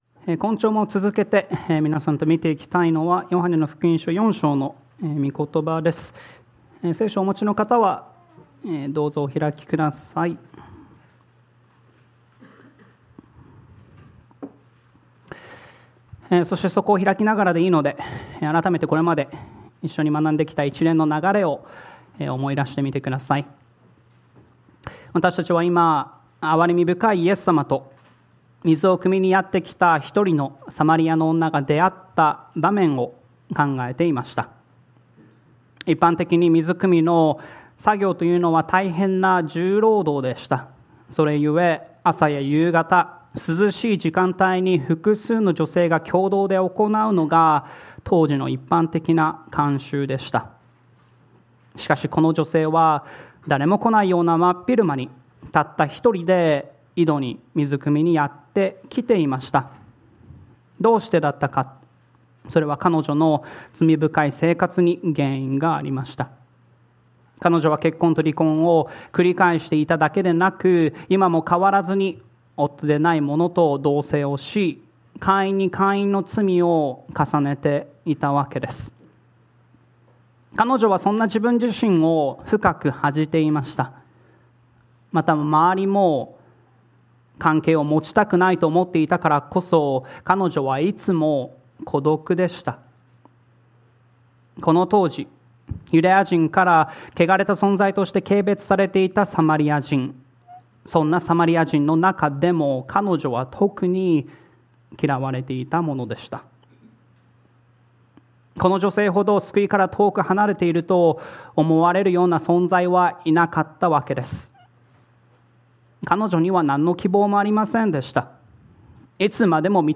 浜寺聖書教会 礼拝メッセージ